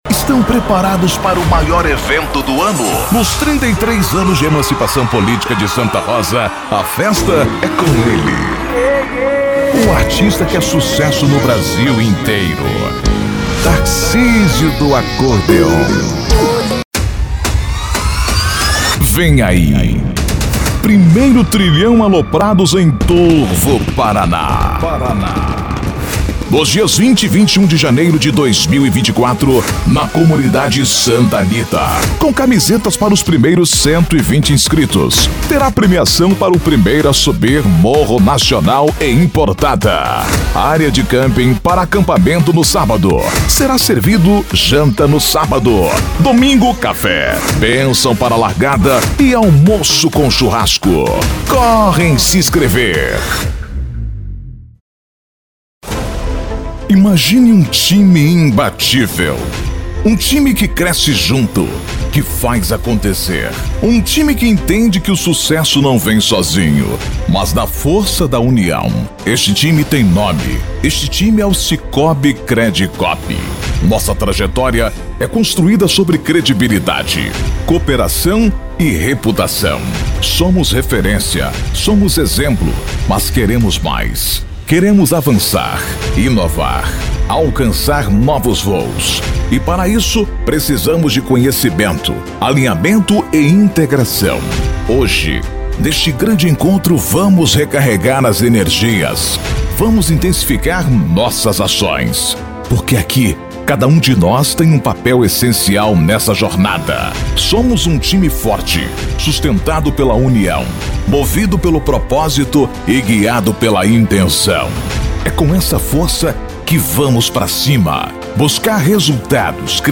DEMO IMPACTO :
Spot Comercial
Impacto